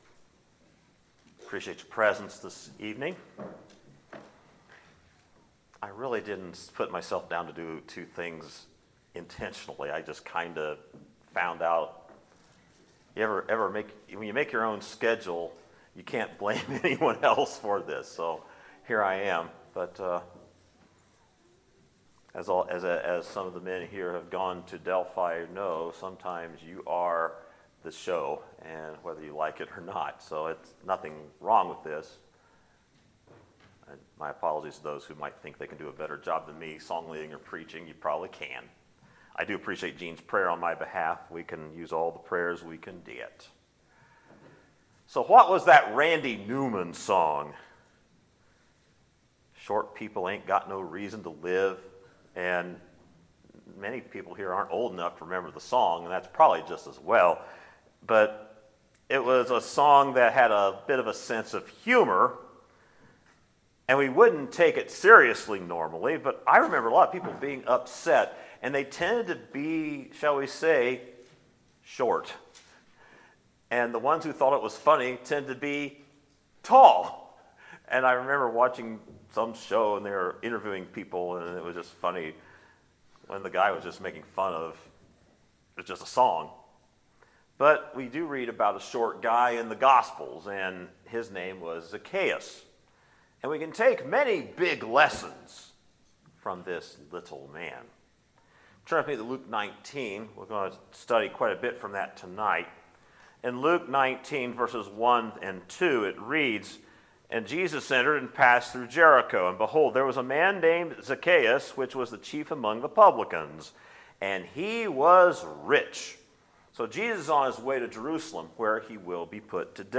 Sermons, May 12, 2019